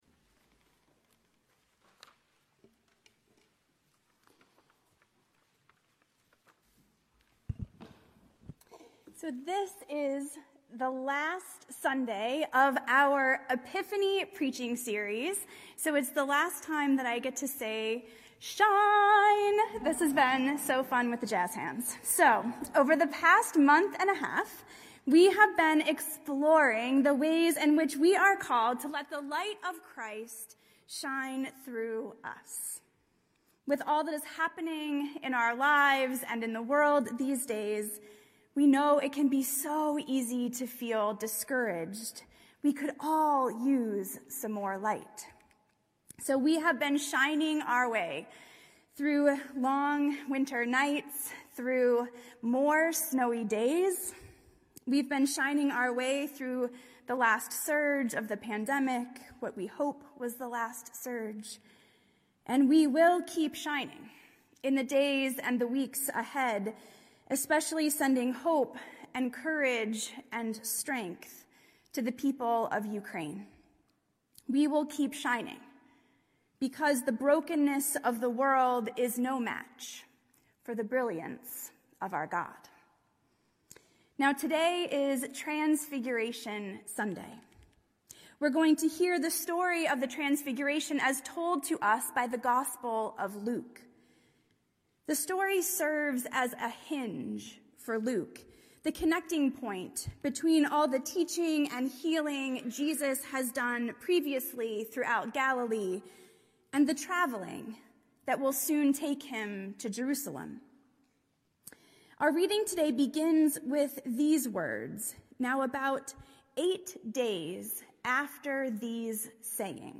A message from the series "Shine."